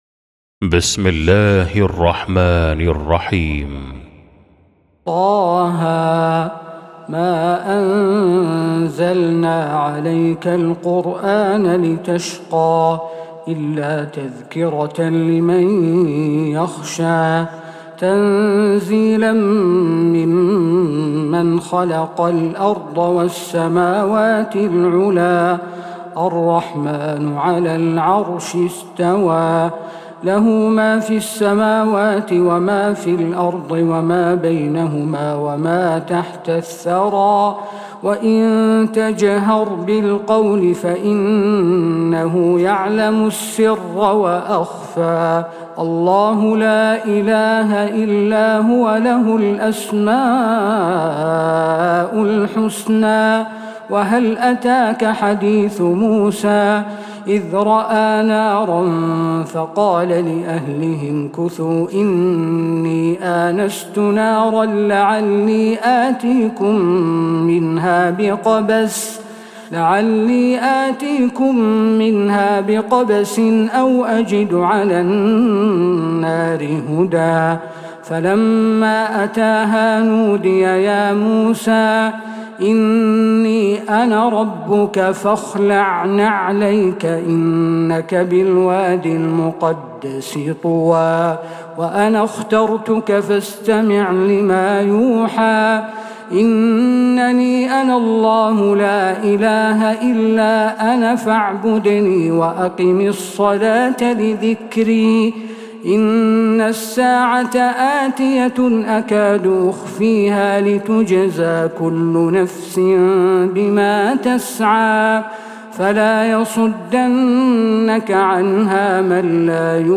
سورة طه | Surah Ta-Ha > مصحف تراويح الحرم النبوي عام 1446هـ > المصحف - تلاوات الحرمين